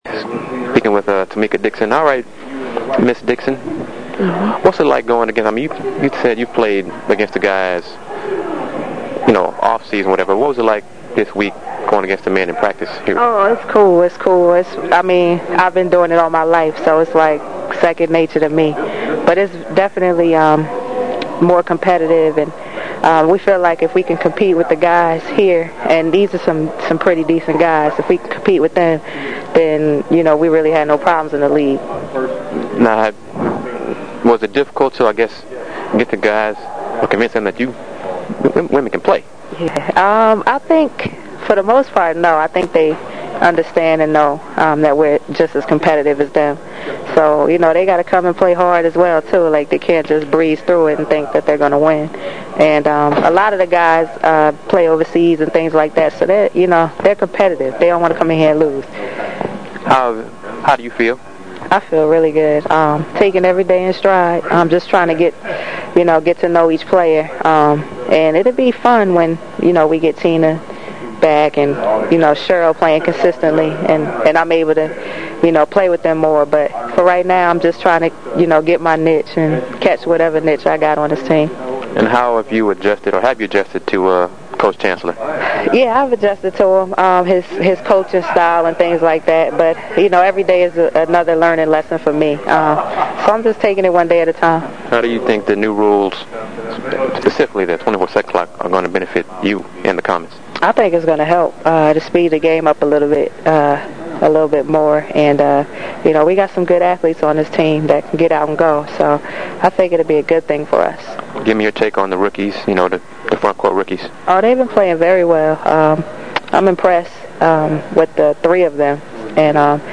5/12: Listen to HRR chat w/ Comets guard Tamecka Dixon